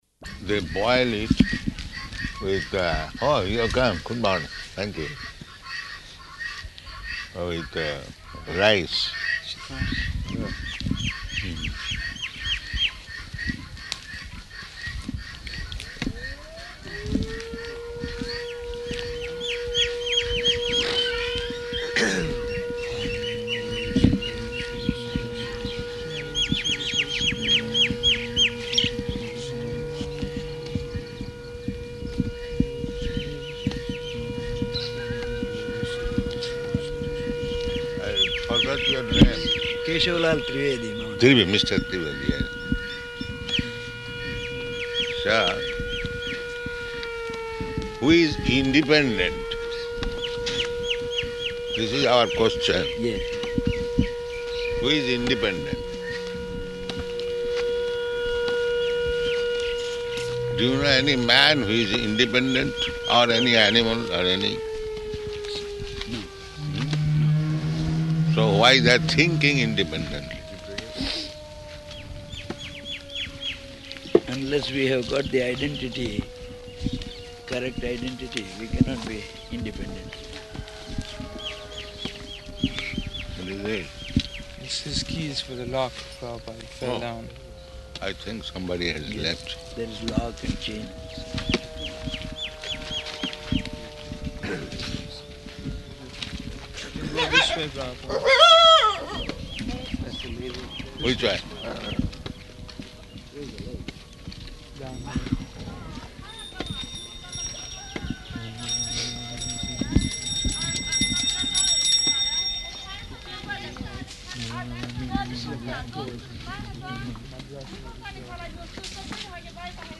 Morning Walk --:-- --:-- Type: Walk Dated: January 3rd 1976 Location: Nellore Audio file: 760103MW.NEL.mp3 Prabhupāda: They boil it with...